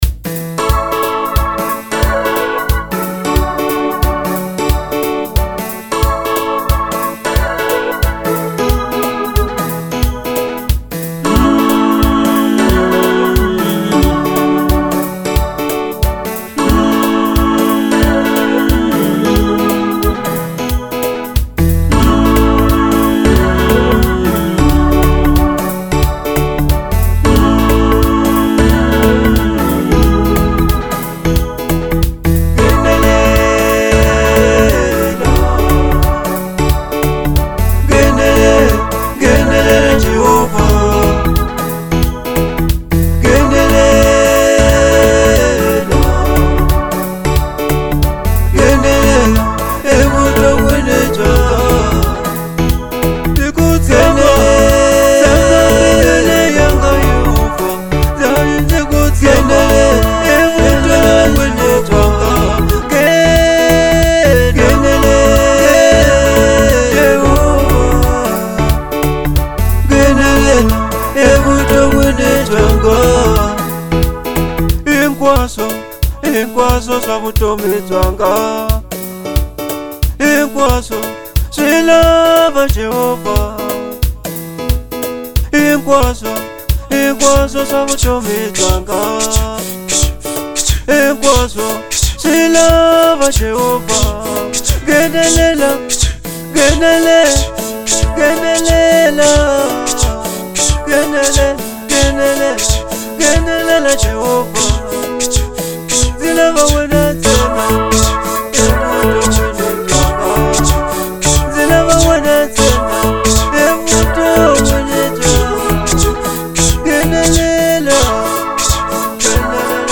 03:54 Genre : Gospel Size